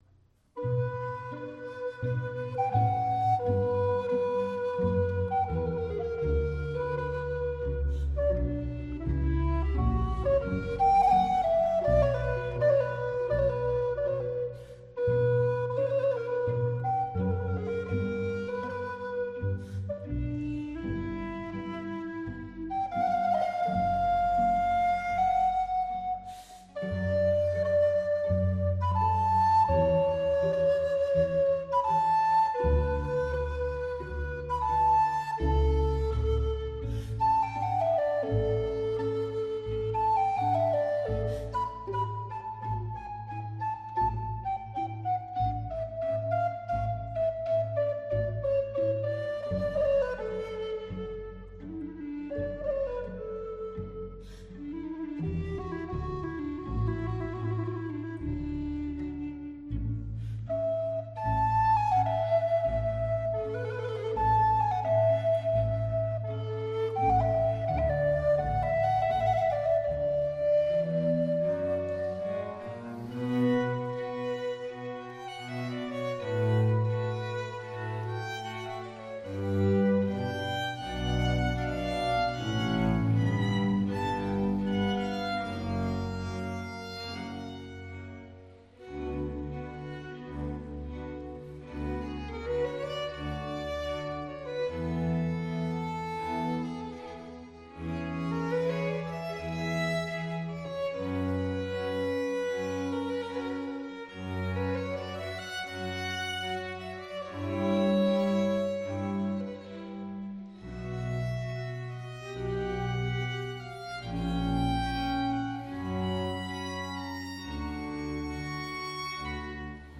La Senna festeggiante, RV 693, Sinfonia: II. Andante molto · Dorothee Oberlinger · Antonio Vivaldi · I Sonatori de la Gioiosa Marca